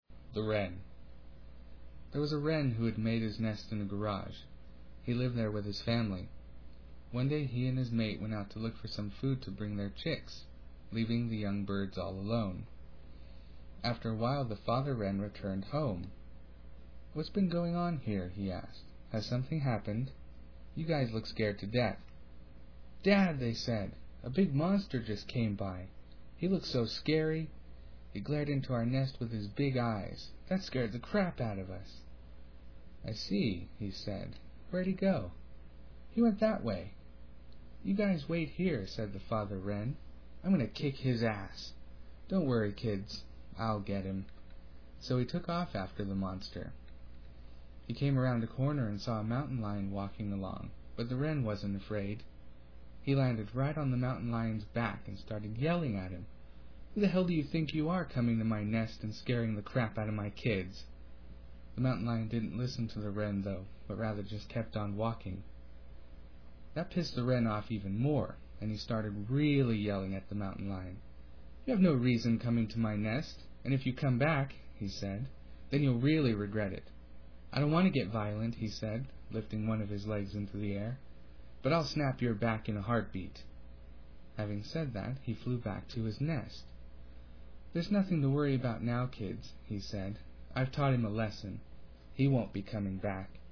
Western American
Listen to this translation narrated with native pronunciation:
Location: Sacramento, California, USA
english-western.mp3